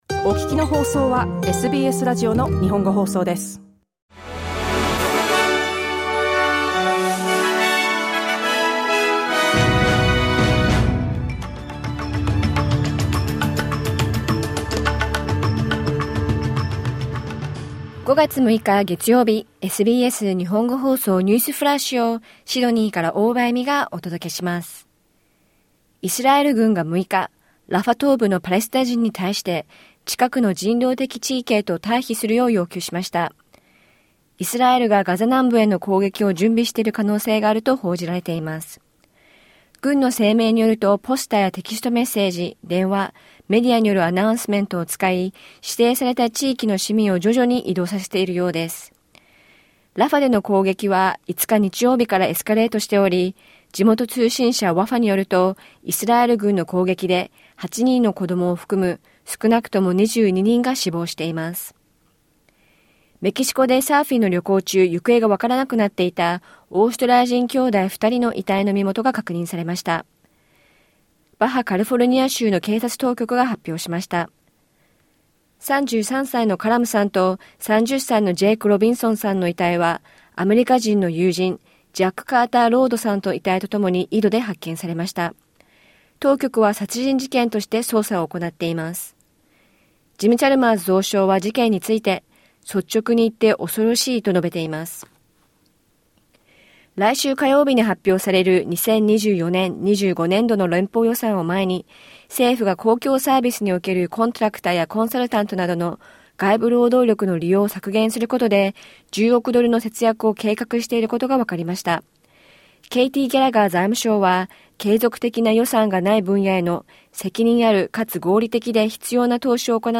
SBS日本語放送ニュースフラッシュ 5月6日月曜日